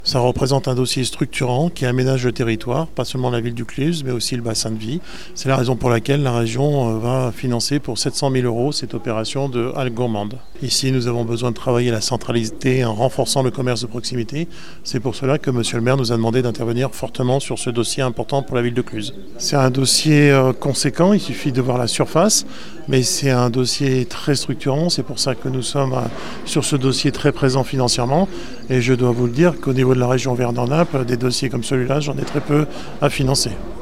Installé dans un bâtiment du milieu du XIXe siècle qui a vu se succéder d’importants commerces de la ville, le projet bénéficie du soutien financier du fonds friche mais pas seulement Philippe Meunier, vice-président de la région Auvergne-Rhône-Alpes, en charge de l’aménagement du territoire nous en parle.